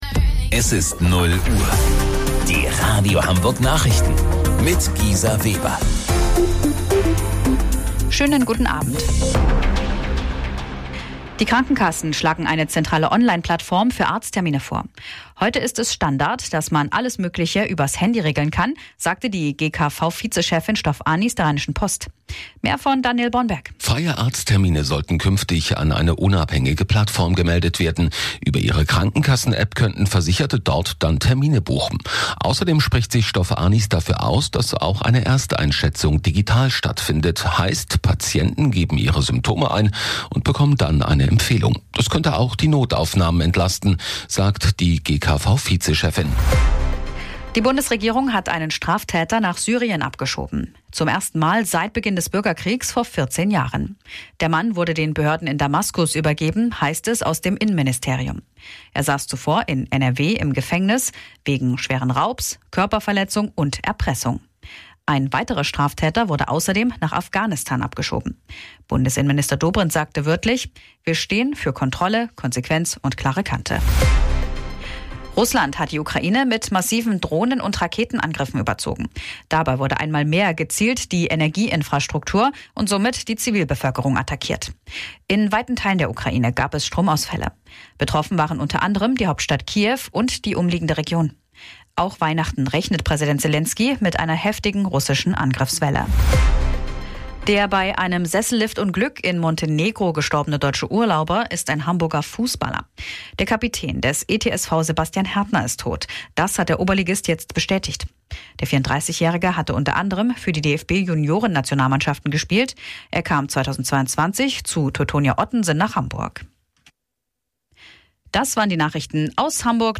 Radio Hamburg Nachrichten vom 24.12.2025 um 00 Uhr